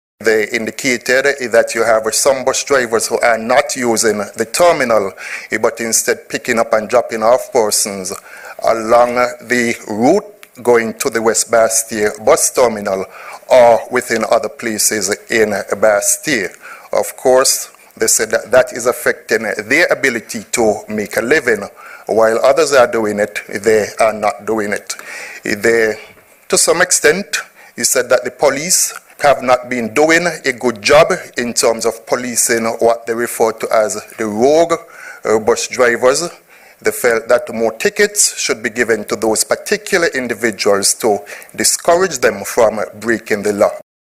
Here is Minster Richards:
Deputy Prime Minister, the Hon. Shawn Richards.